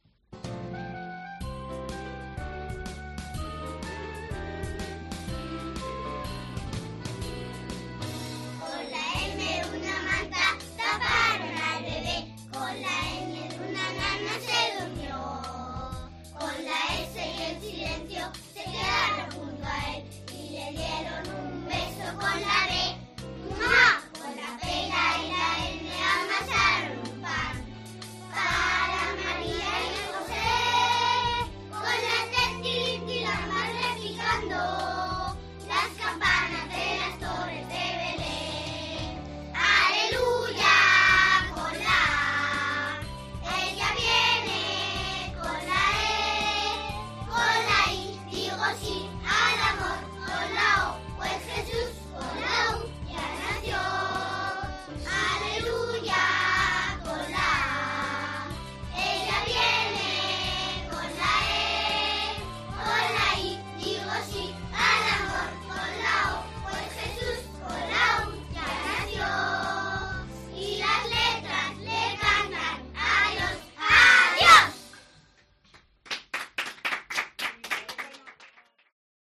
Villancico de Sagrado Corazón que han quedado segundos clasificados